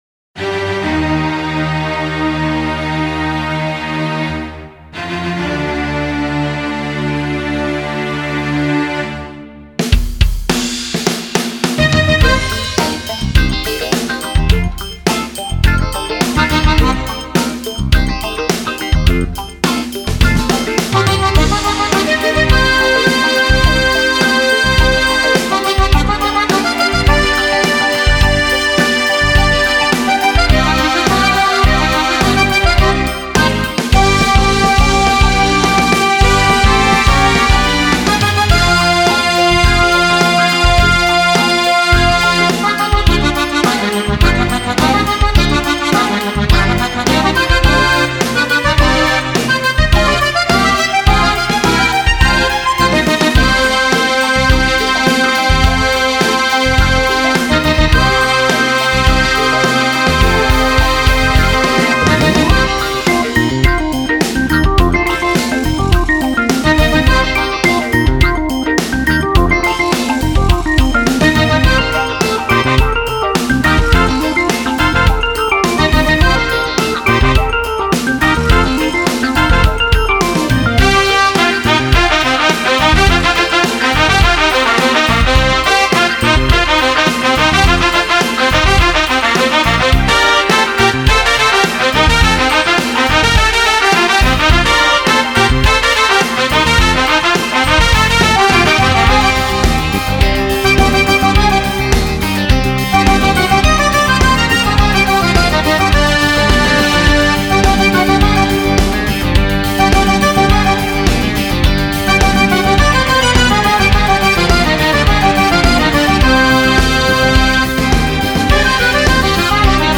cumbia dance